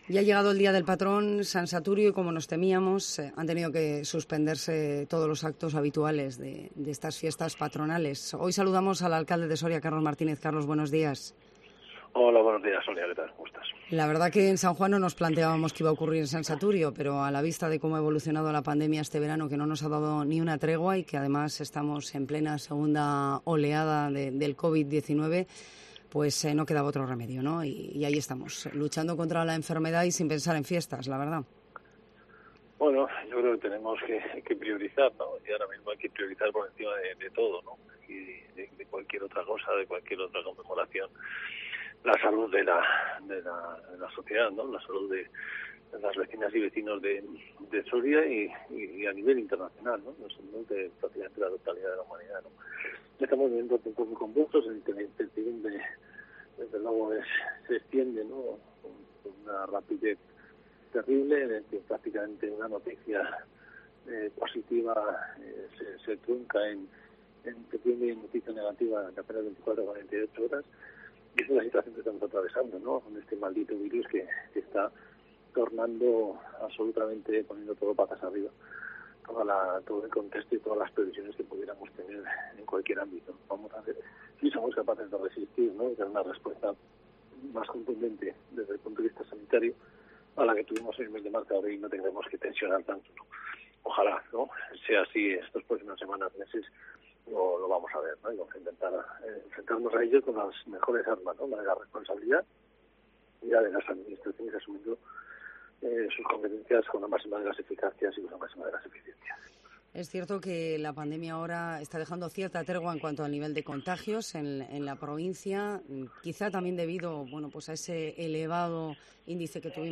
El alcalde, Carlos Martínez, pasa por los micrófonos de Cope Soria el día del patrón, San Saturio